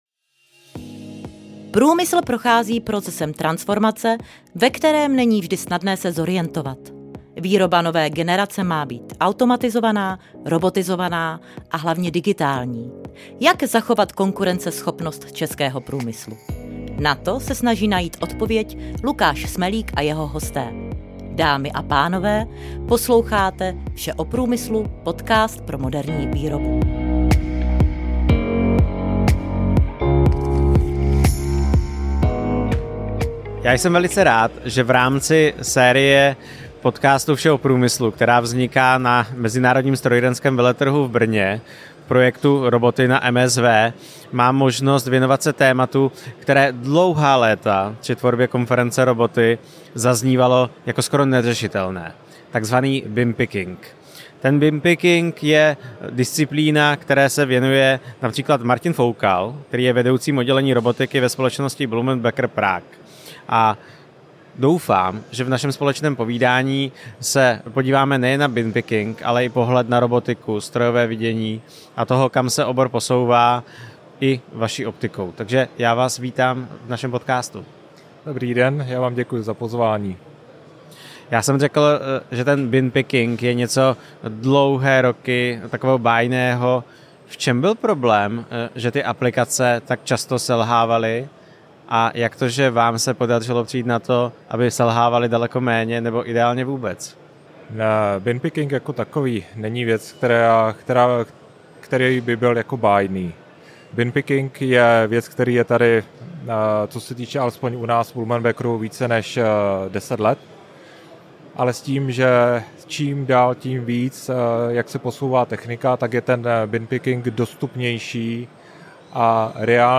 V rozhovoru se podíváme na bin picking, ale také na robotiku, strojové vidění i to, kam se obor posouvá.